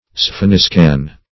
spheniscan - definition of spheniscan - synonyms, pronunciation, spelling from Free Dictionary Search Result for " spheniscan" : The Collaborative International Dictionary of English v.0.48: Spheniscan \Sphe*nis"can\, n. (Zool.) Any species of penguin.